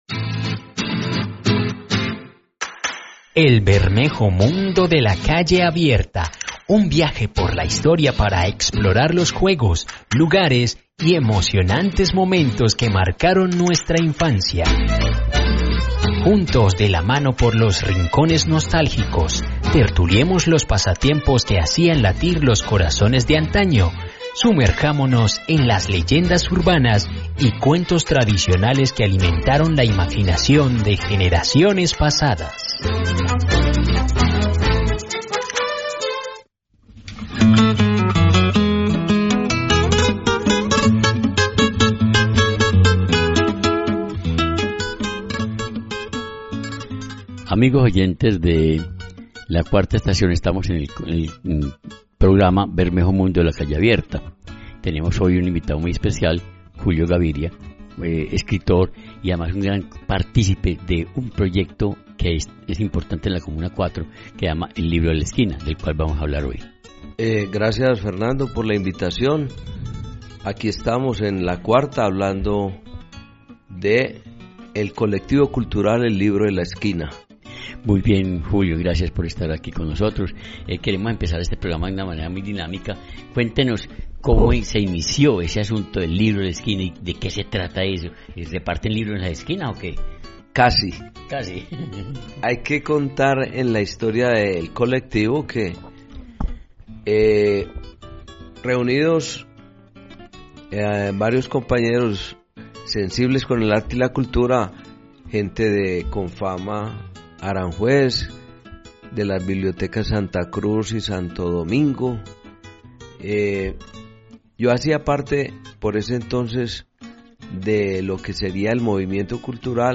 Si eres amante de los relatos que tocan el alma, no puedes perderte esta conversación llena de curiosidades, risas y mucha inspiración.